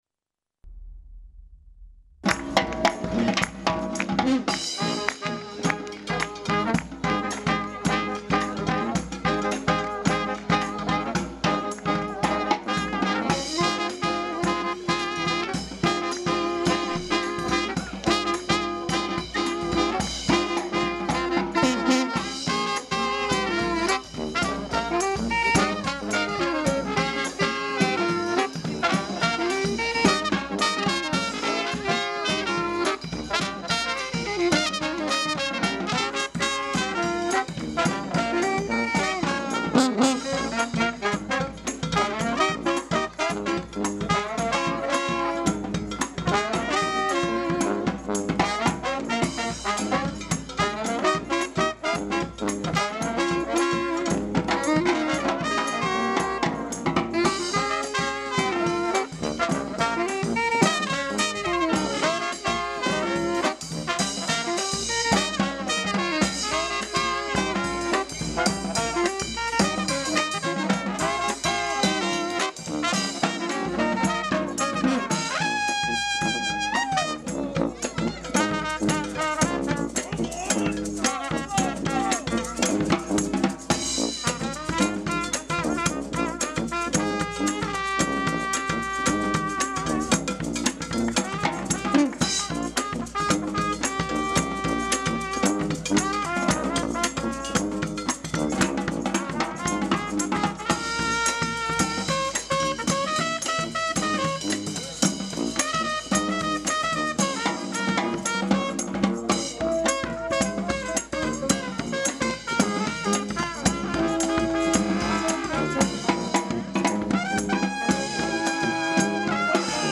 grosse caisse et percussion
caisse claire et percussion
soubassophone
trompette
trombone
saxophone ténor